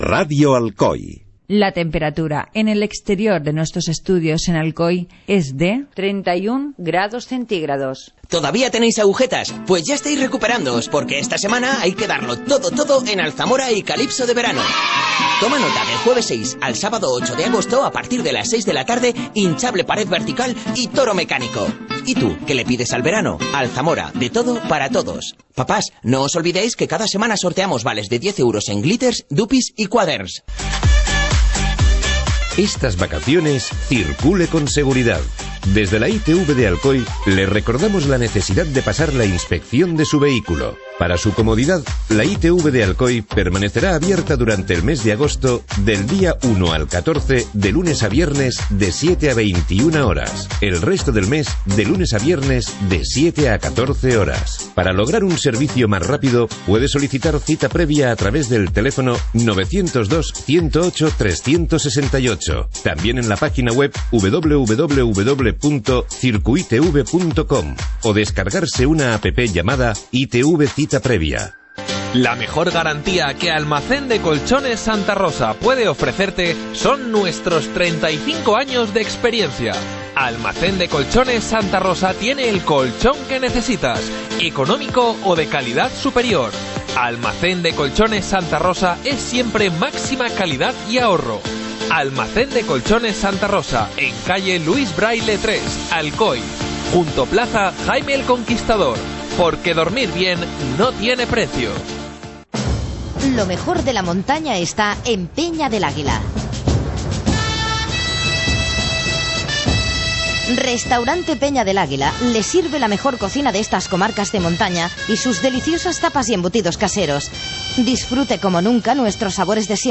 Acaba de llegar de Italia y pronto se marcha a Colombia, donde se estrena a la batuta de una orquesta joven, pero hemos podido hablar con él en el Hoy por Hoy.